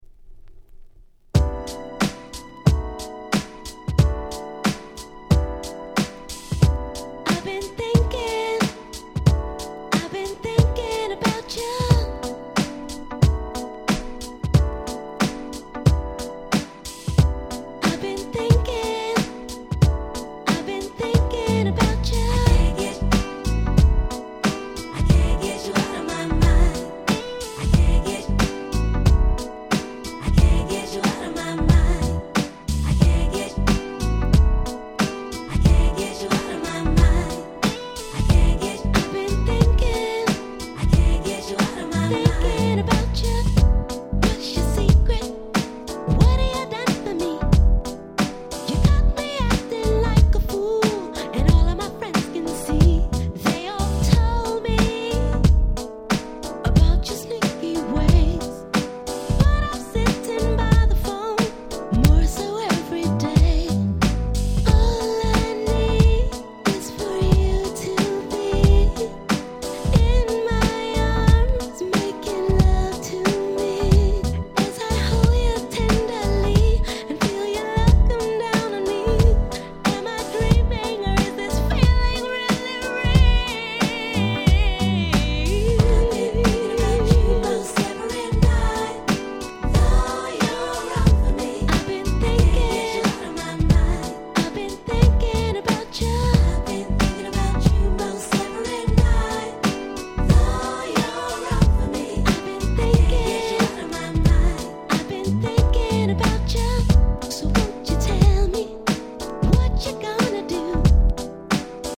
95' Nice UK R&B !!
切ないMelodyが堪りません！